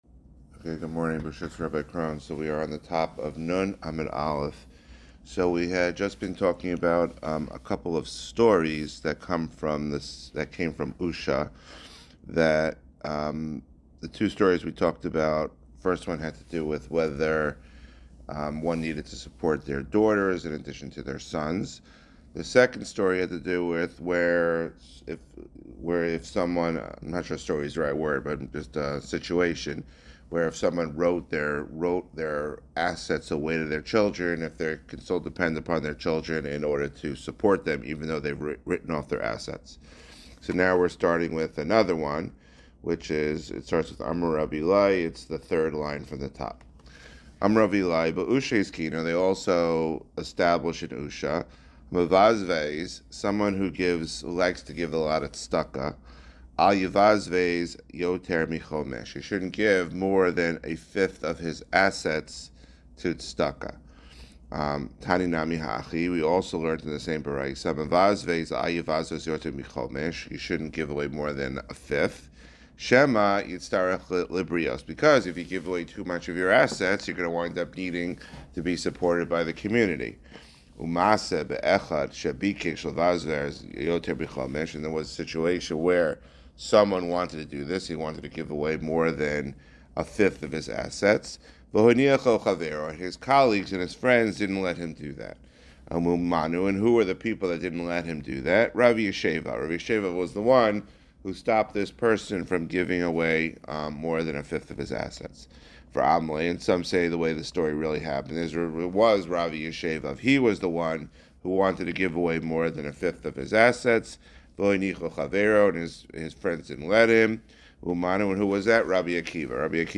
Guest shiur